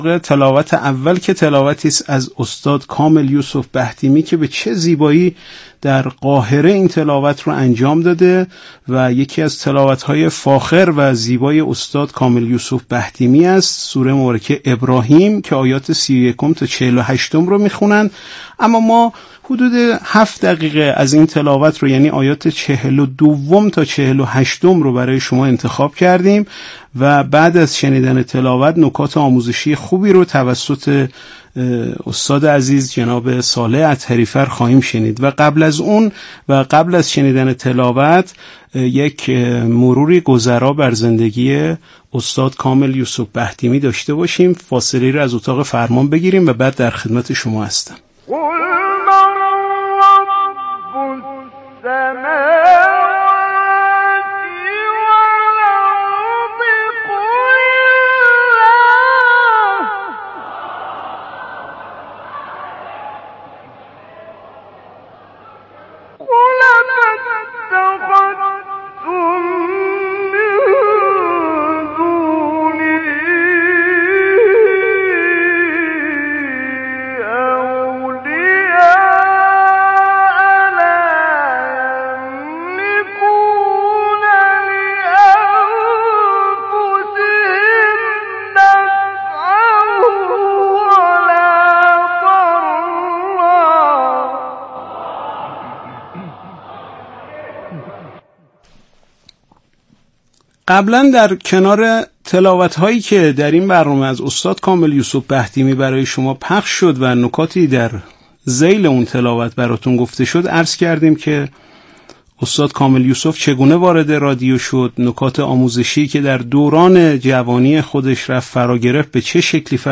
تلاوت معجزه‌آسای «کامل یوسف» از سوره فصلت/ اذانی که برای عموم منتشر نشد